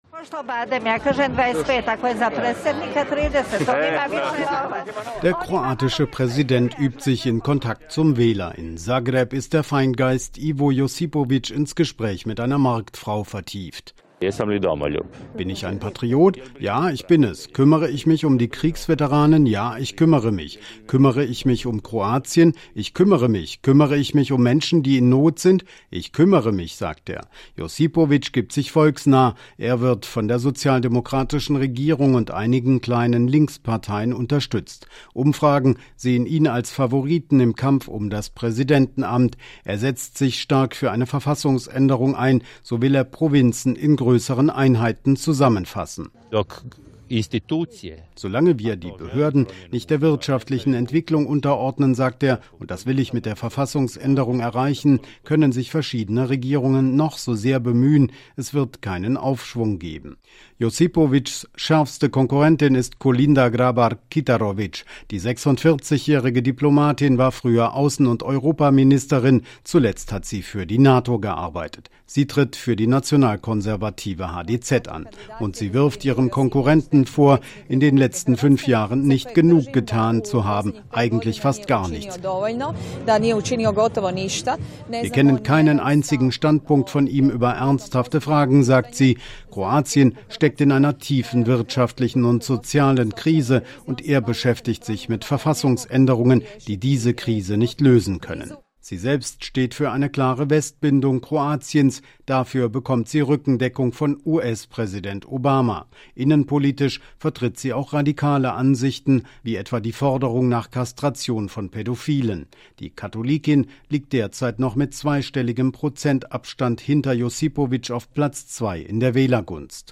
Vorbericht-Präsidentenwahl-in-Kroatien.mp3